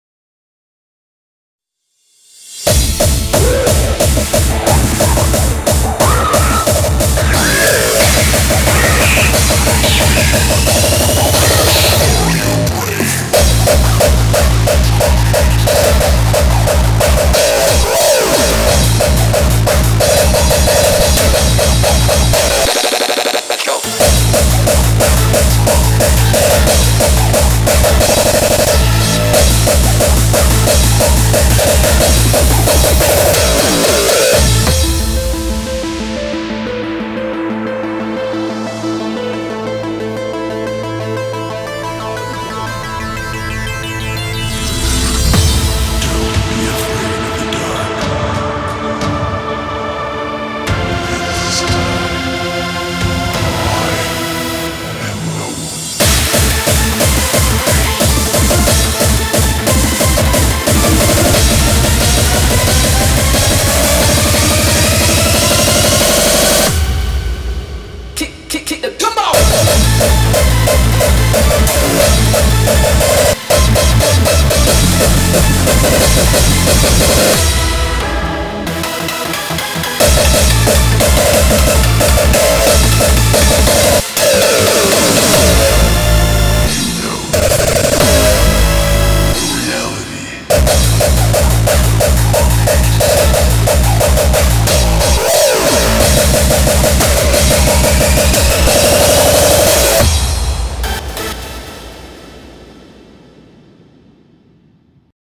BPM45-360
Audio QualityPerfect (High Quality)
TIP: Main BPM is 180.